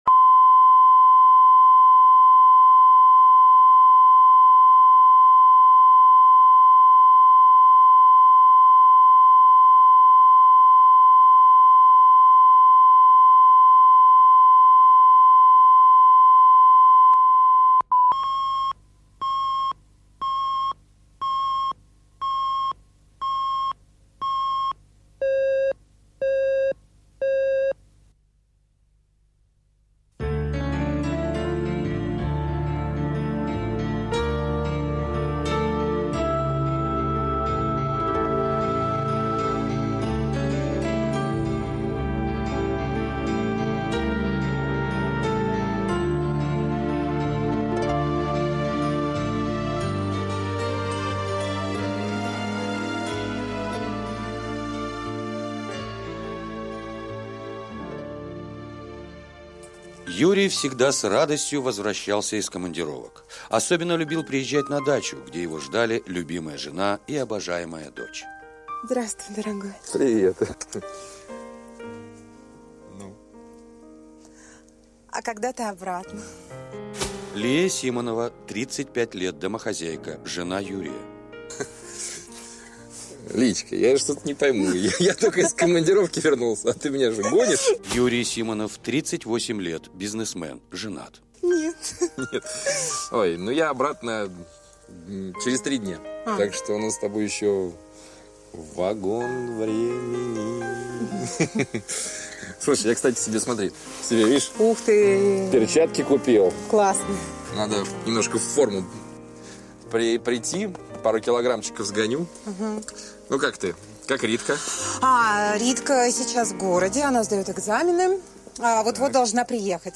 Аудиокнига Белый колдун | Библиотека аудиокниг